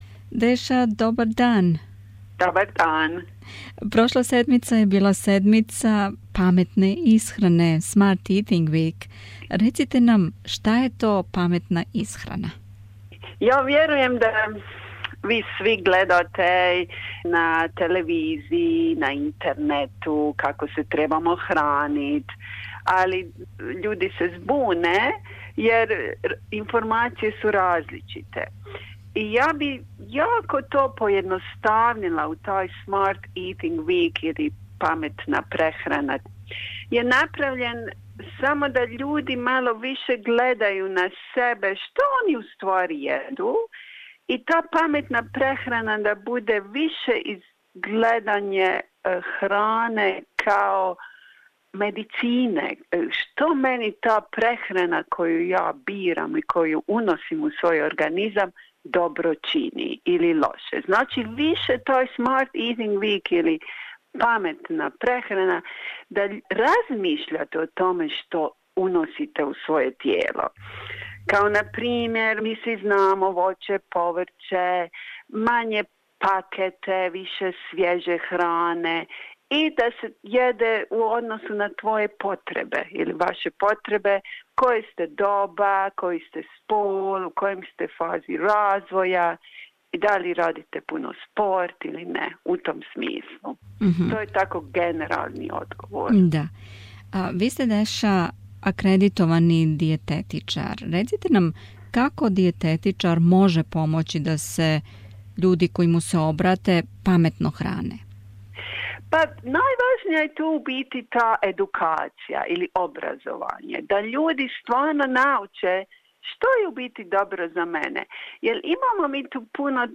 Кажу да треба радити мање, не више -али кад је реч о храни и исхрани, по некад се питамо како да изаберемо храну која одговора баш нашем организму. У оквиру обележавања Недеље паменте исхране у разговору